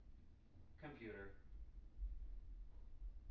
wake-word
tng-computer-200.wav